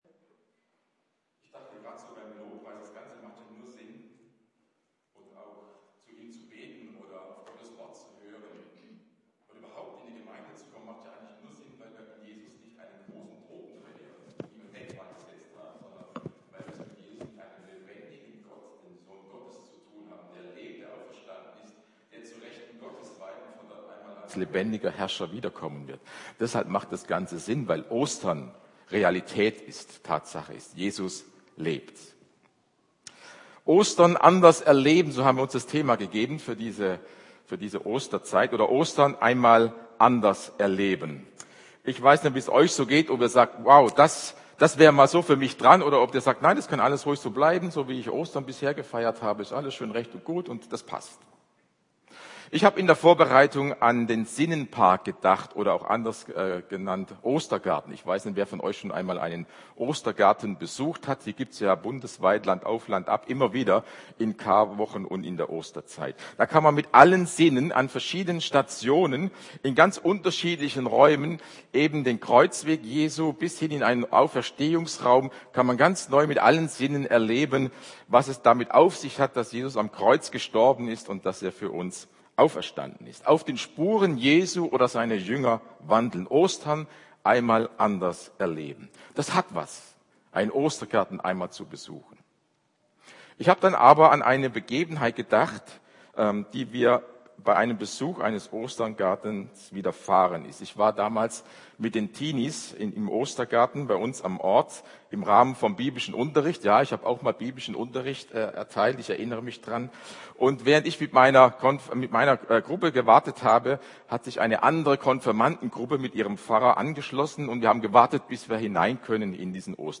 Predigt
Ostergottesdienst